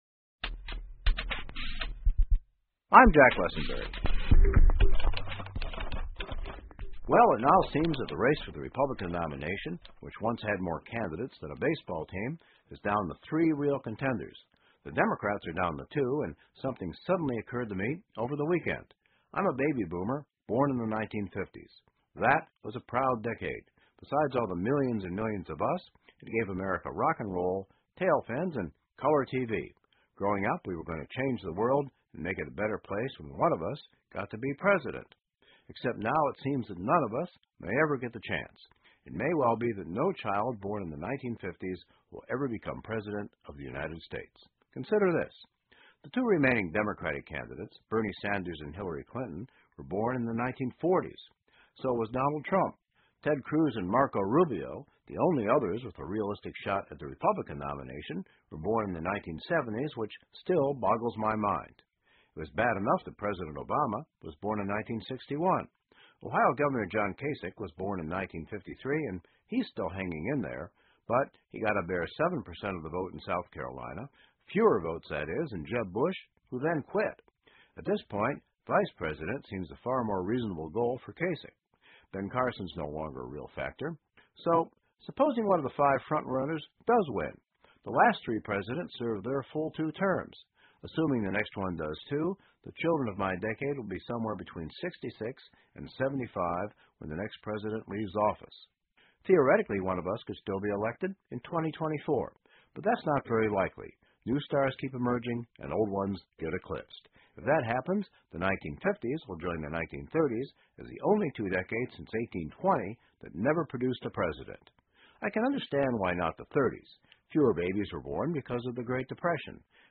密歇根新闻广播 出生在婴儿潮时期的人没抓住当总统的机遇 听力文件下载—在线英语听力室